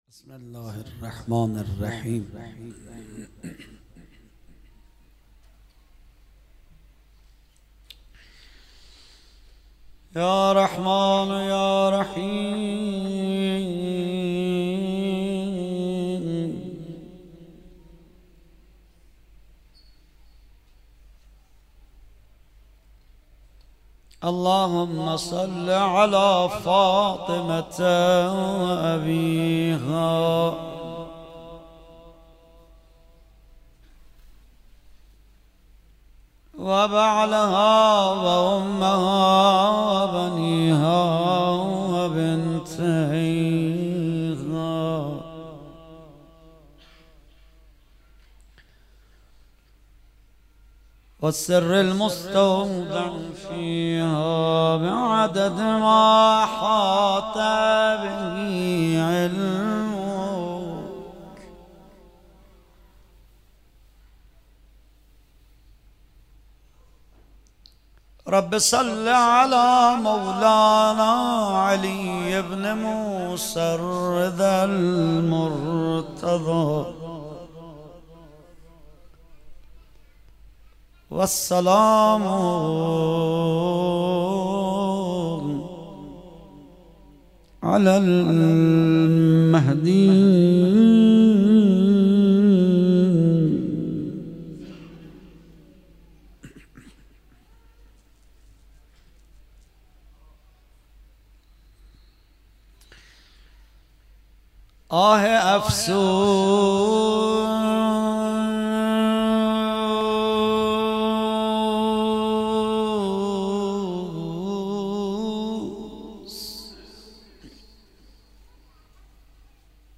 شب هشتم محرم 97 - روضه